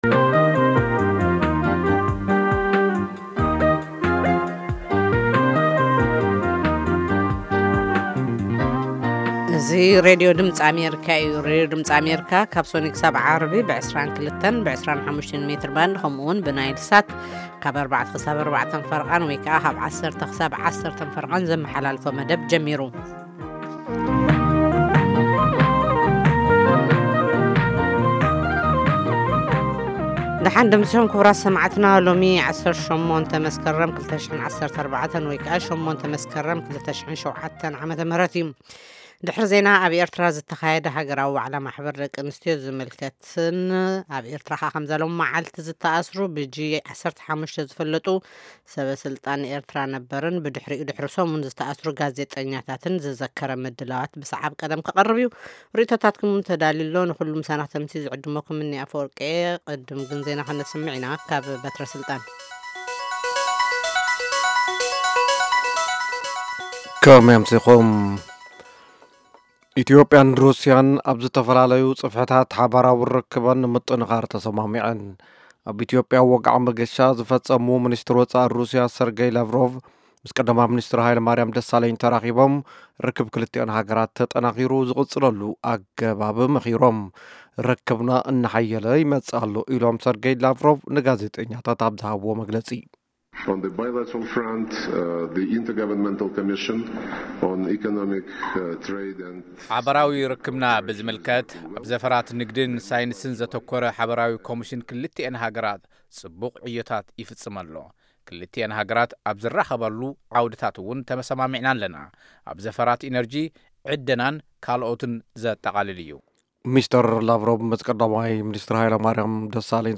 Half-hour broadcasts in Tigrigna of news, interviews with newsmakers, features about culture, health, youth, politics, agriculture, development and sports on Monday through Friday evenings at 10:00 in Ethiopia and Eritrea.